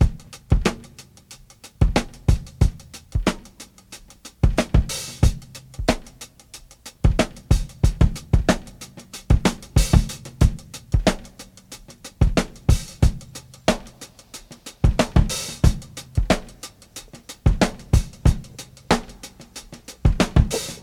• 92 Bpm Breakbeat C Key.wav
Free drum beat - kick tuned to the C note. Loudest frequency: 749Hz
92-bpm-breakbeat-c-key-U1L.wav